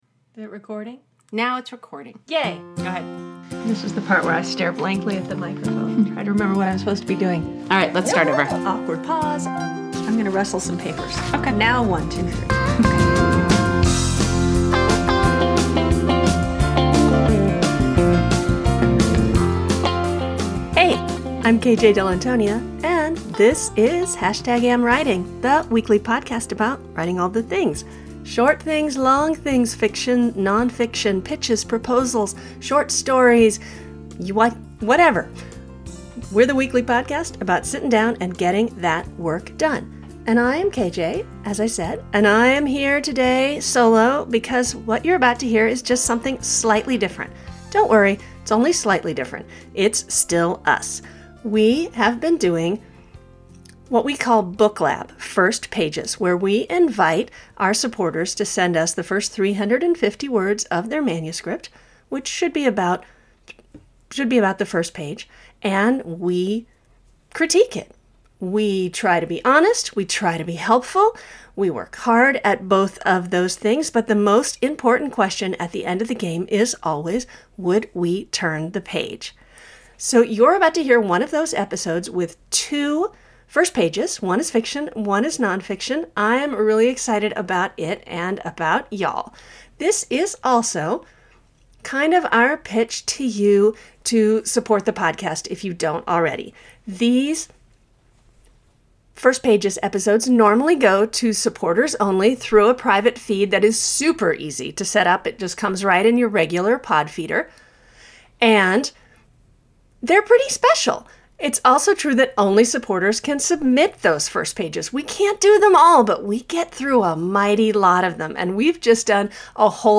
We will read the page aloud on the podcast and discuss with a single thought in mind: Would we keep reading?
On the podcast, we’ll read the page aloud and then each cast our “vote”—would we keep going?